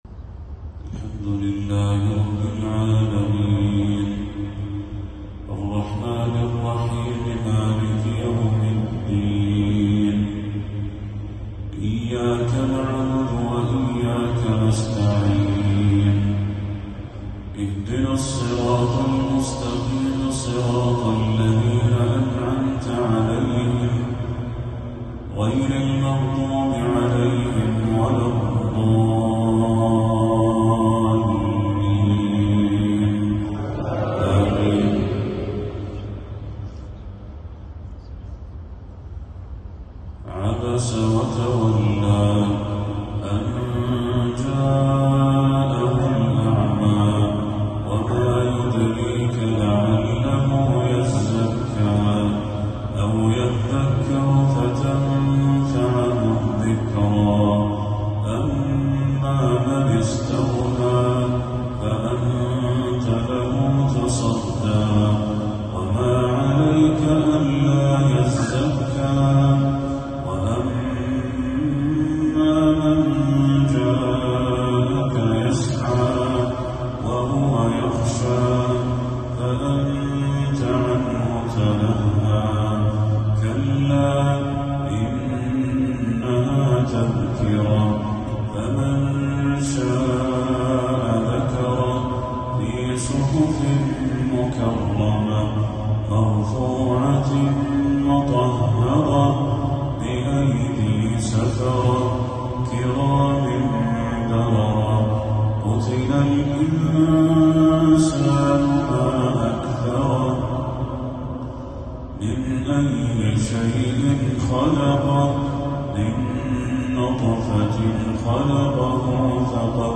تلاوة لسورتي عبس والبلد
عشاء 8 صفر 1446هـ